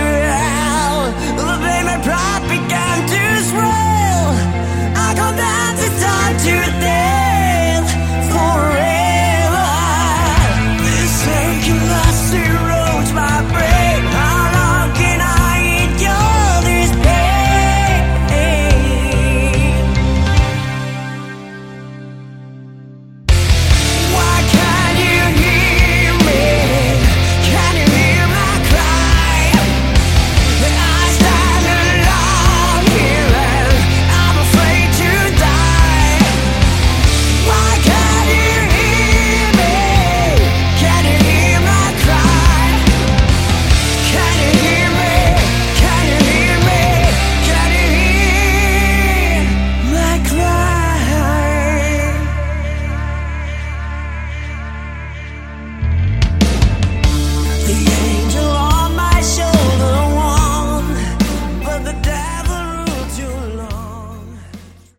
Category: Hard Rock
Guitars
Drums
Vocals
Bass